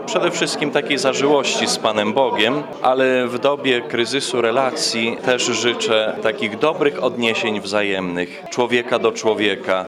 To już piękna świąteczna tradycja – wigilia w Radiu 5 Ełk.